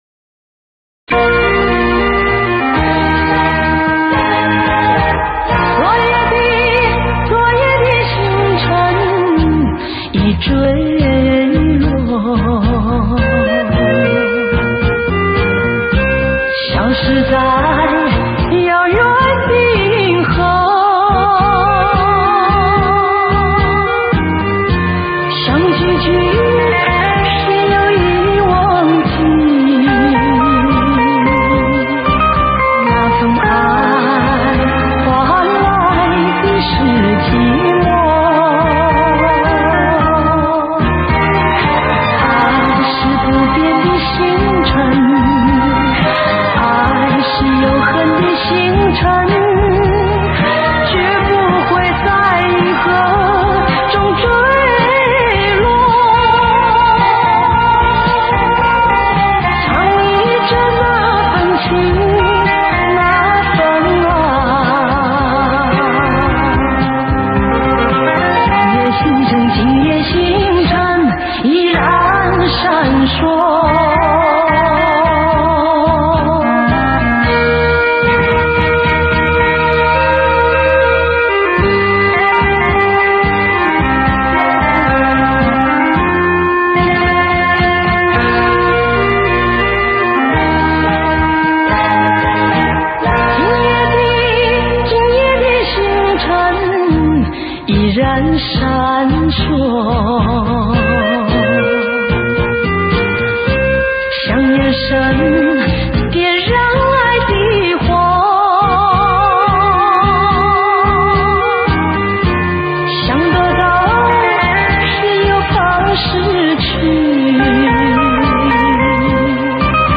给你从电视上录取的原音
音质稍好些